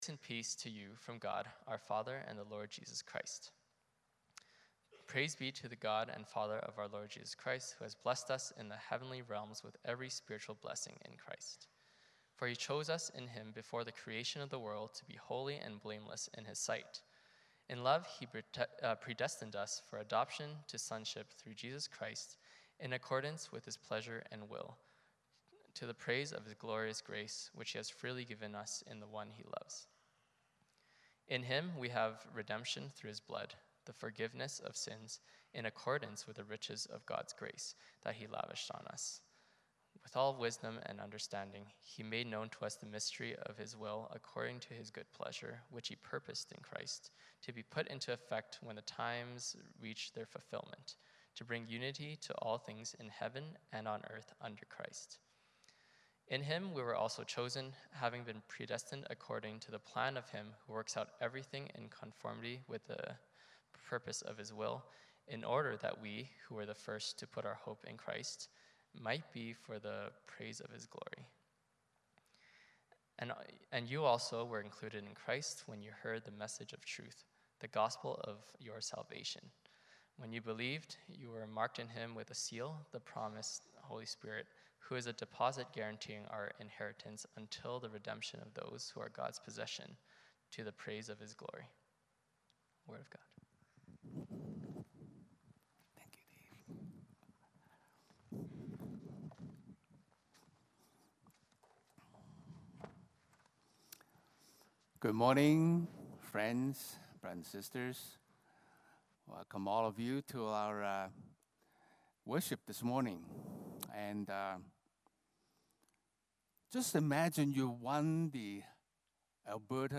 Passage: Ephesians 1:1-14 Service Type: Sunday Morning Service Passage